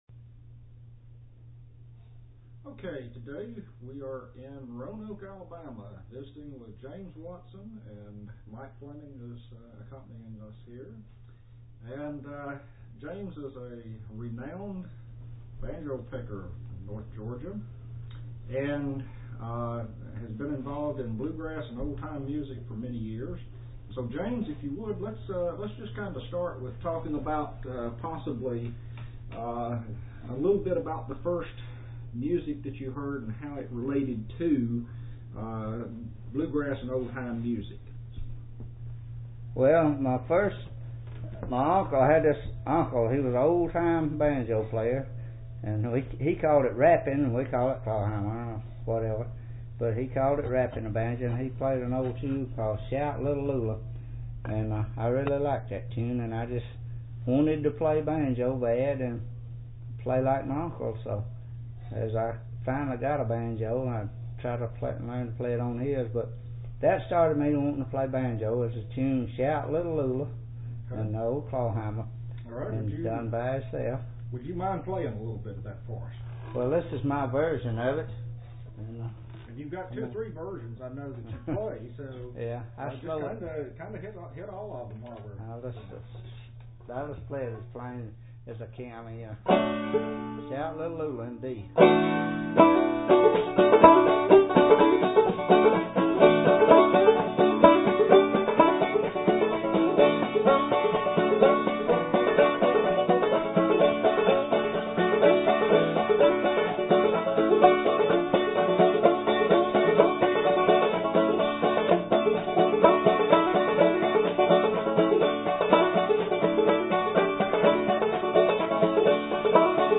Listen to an interview with an original banjo stylist and performer!